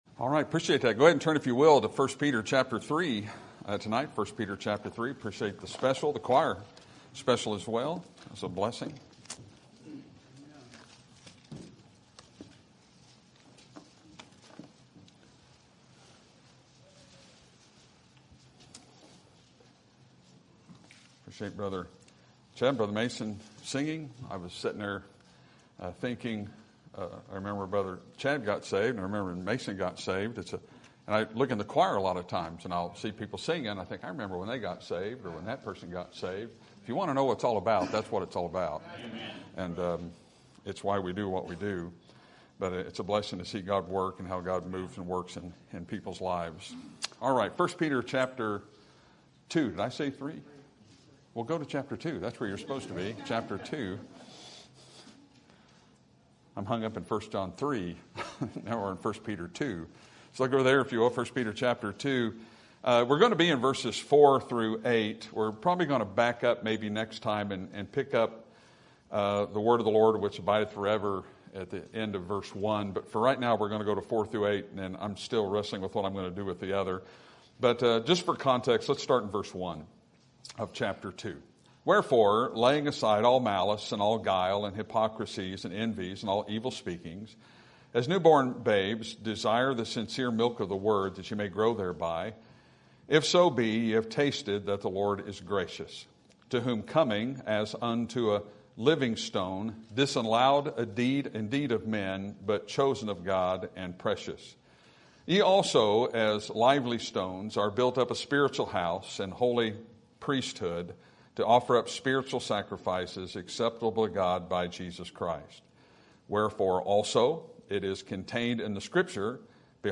Sermon Date